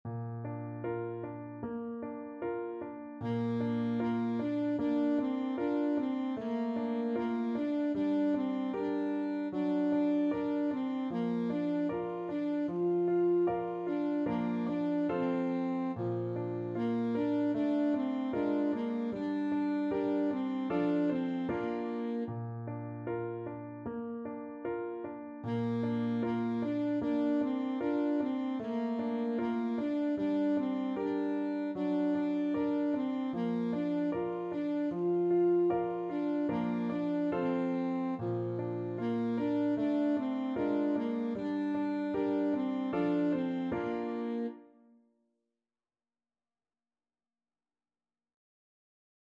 Alto Saxophone
Traditional Music of unknown author.
Andante =c.76
2/4 (View more 2/4 Music)
F4-F5